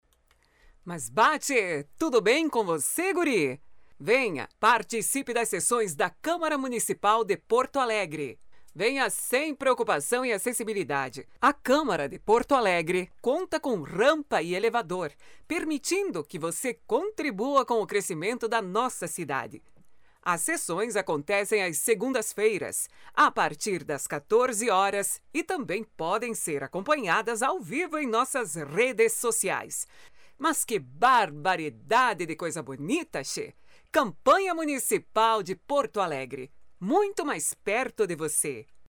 LOC SOTAQUE GAÚCHO: